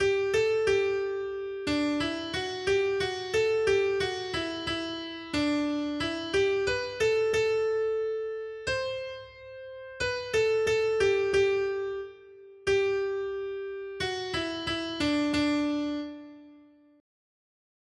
Noty Štítky, zpěvníky ol420.pdf responsoriální žalm Žaltář (Olejník) 420 Skrýt akordy R: Vzpomínáme, Bože, na tvé milosrdenství ve tvém chrámě. 1.